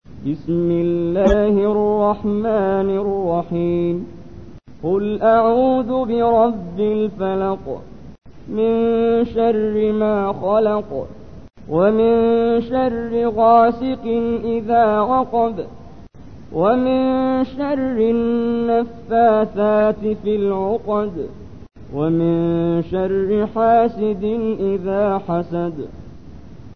تحميل : 113. سورة الفلق / القارئ محمد جبريل / القرآن الكريم / موقع يا حسين